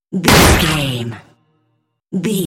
Dramatic stab
Sound Effects
Atonal
heavy
intense
dark
aggressive
hits